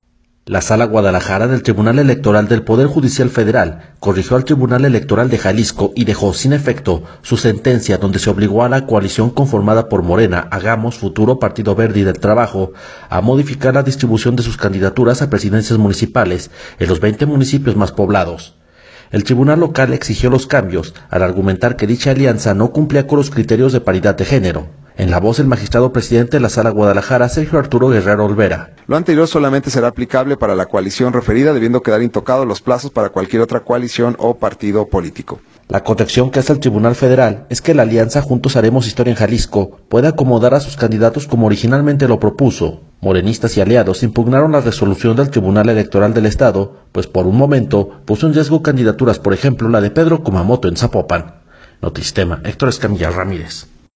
En la voz el magistrado presidente de la Sala Guadalajara, Sergio Arturo Guerrero Olvera.